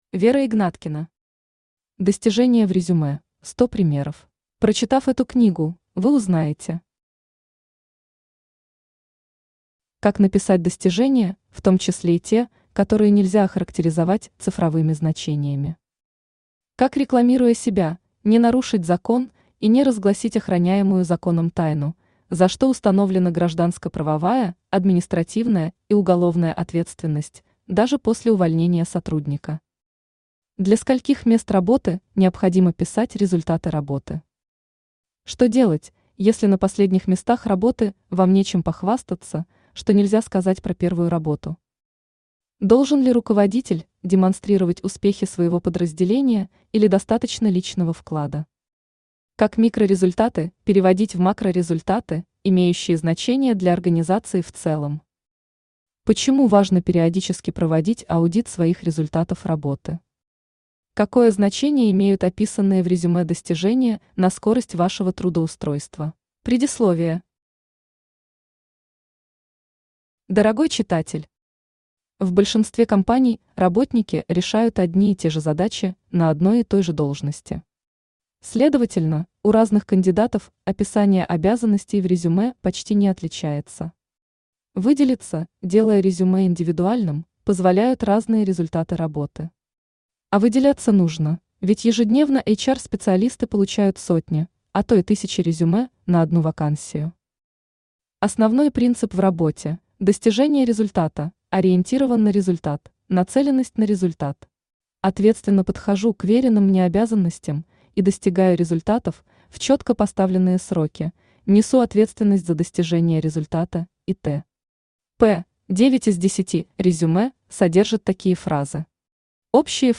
Аудиокнига Достижения в резюме: 100 примеров | Библиотека аудиокниг
Aудиокнига Достижения в резюме: 100 примеров Автор Вера Игнаткина Читает аудиокнигу Авточтец ЛитРес.